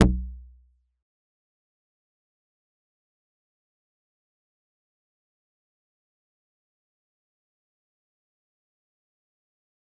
G_Kalimba-E1-mf.wav